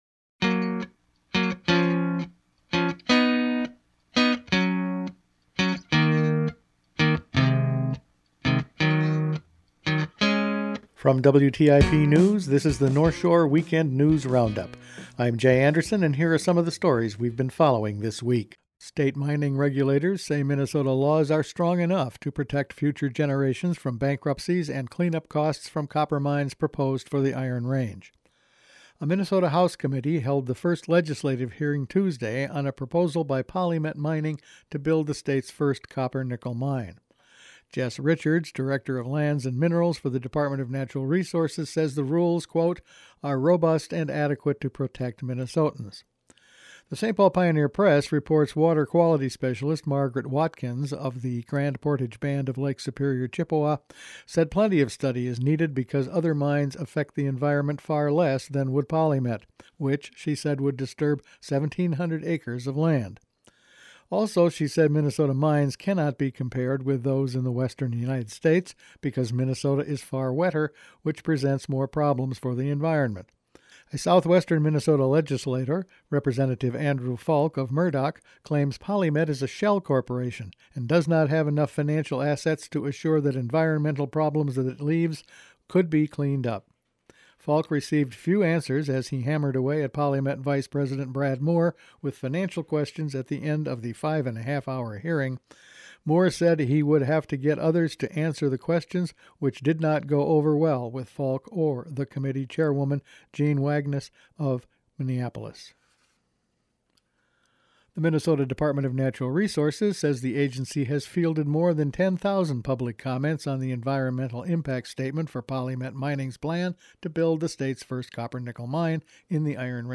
Weekend News Roundup for February 15